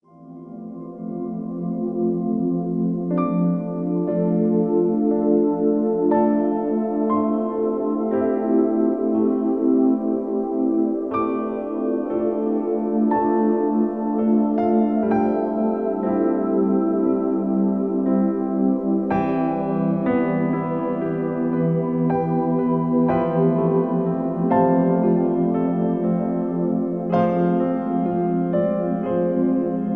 Hier ist die Musik OHNE Sprache.